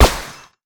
polygon_shoot_sniper_silenced.wav